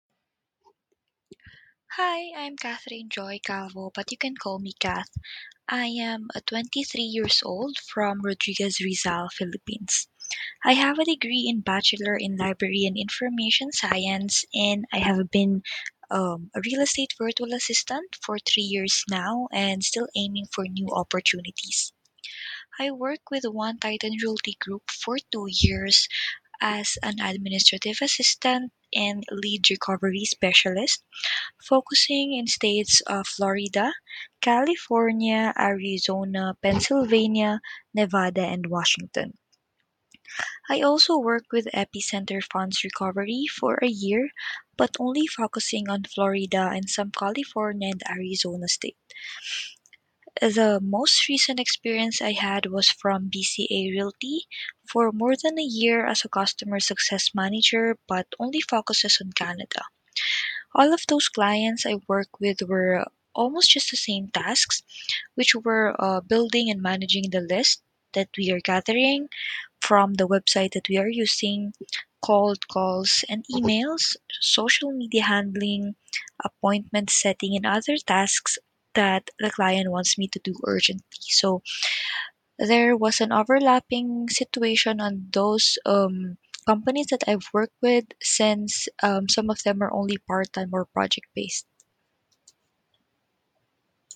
Self Introduction
Voice-recording-Introduction.mp3